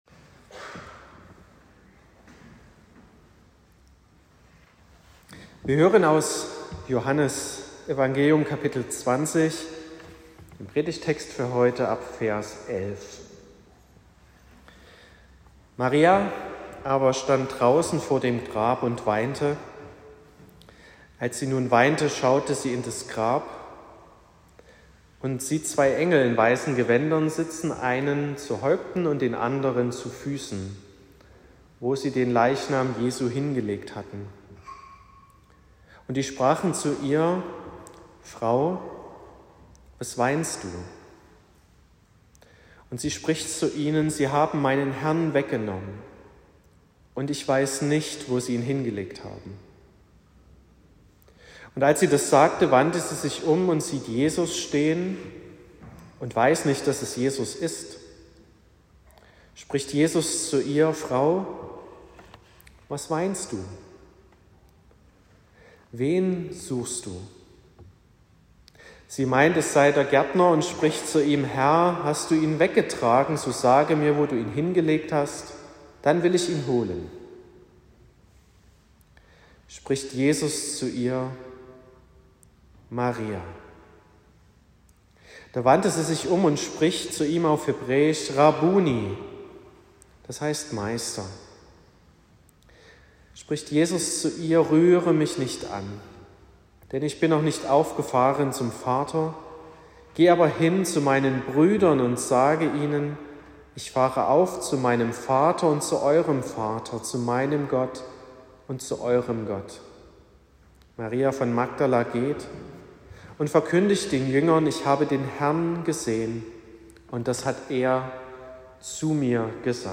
21.04.2025 – Gottesdienst
Predigt (Audio): 2025-04-21_Auferstehung_veraendert_alles.m4a (9,8 MB)